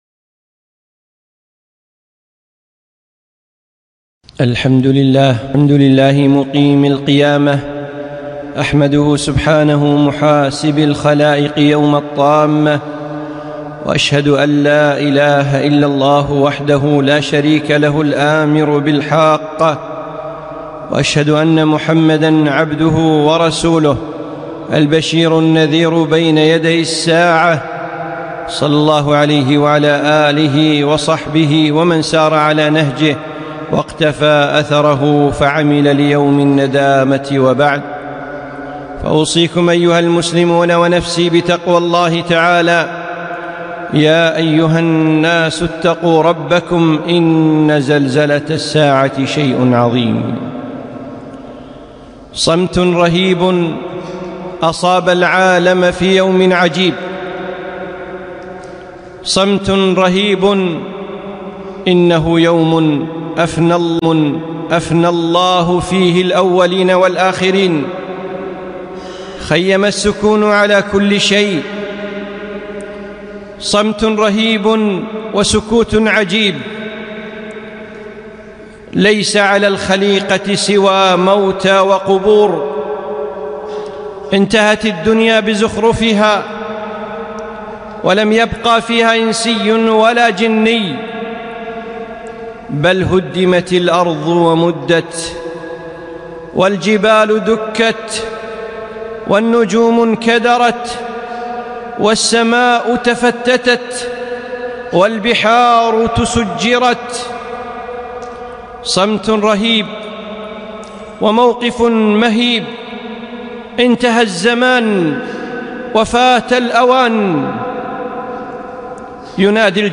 خطبة - صـمت رهـيب